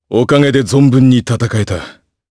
Chase-Vox_Victory_jp.wav